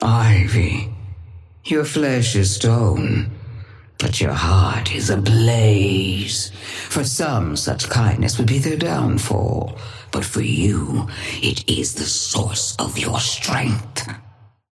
Sapphire Flame voice line - Ivy, your flesh is stone, but your heart is ablaze.
Patron_female_ally_tengu_start_05.mp3